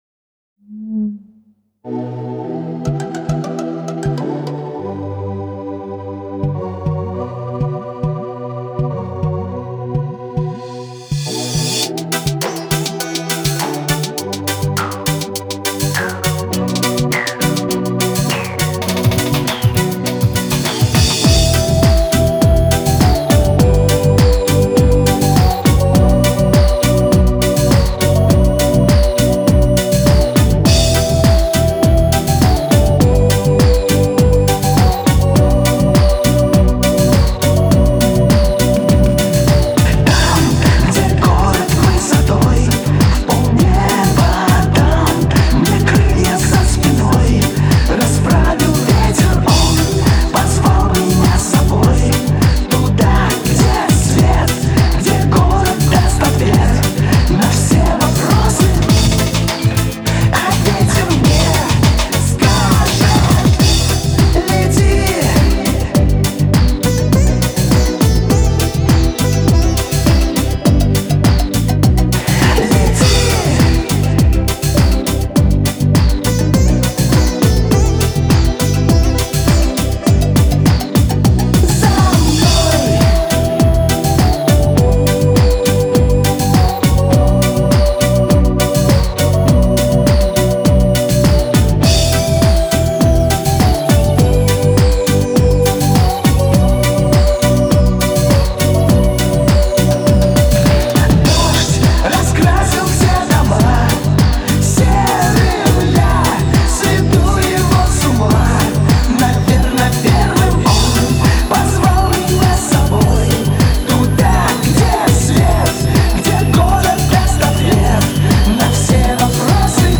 RUS, Dance, 80-90th, Pop, Disco | 20.03.2025 21:19